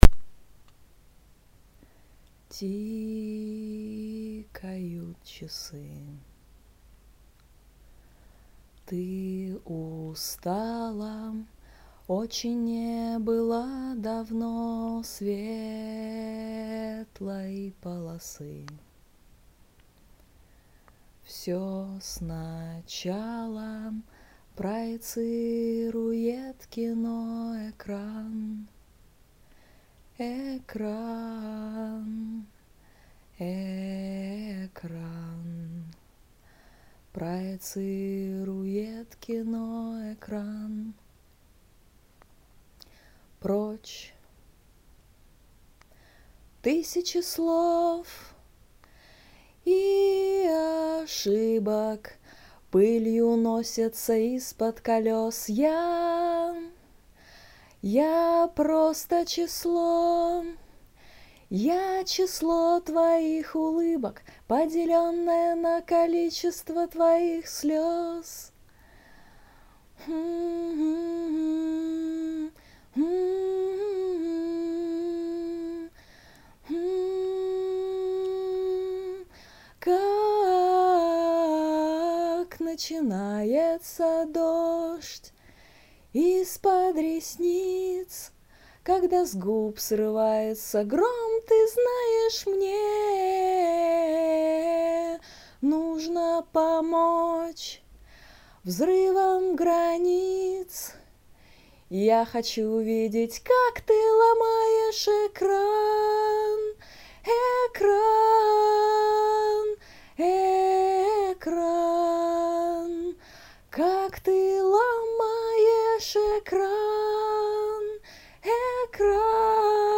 Настроение: певческое
аккомпаниаторы приветствуются, короче)